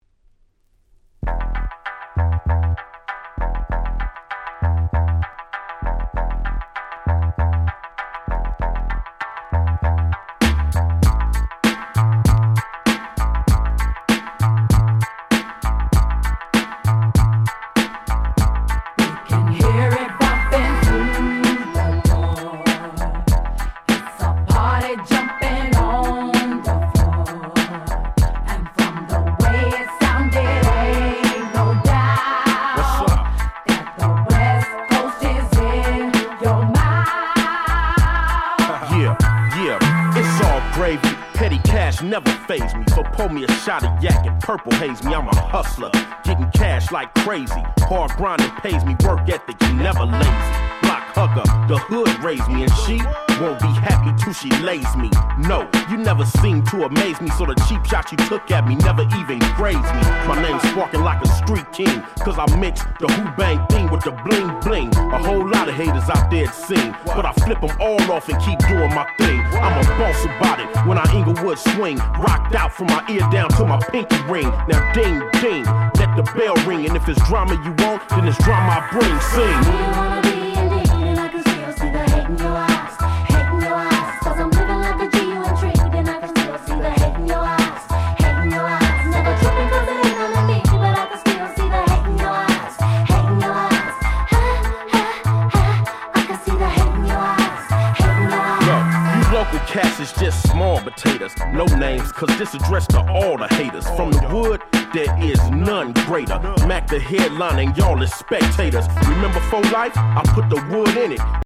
01' Smash Hit West Coast Hip Hop !!
Instrumental